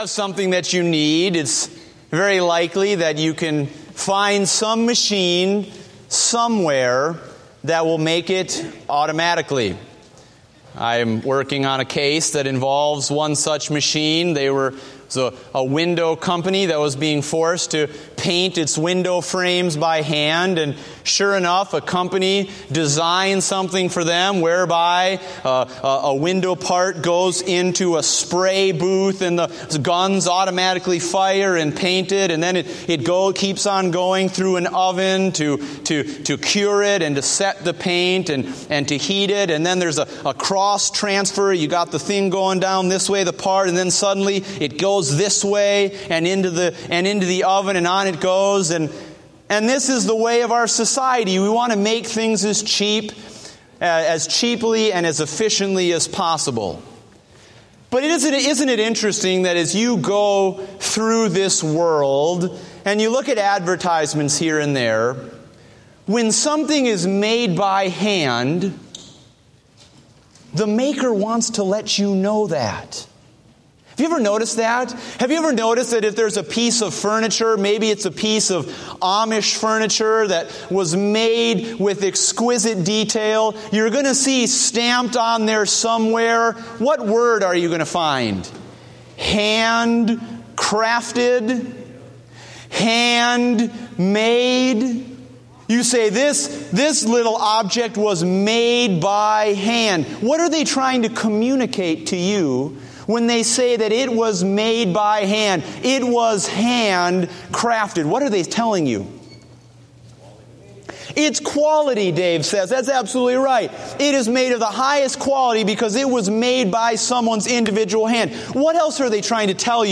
Date: July 12, 2015 (Morning Service)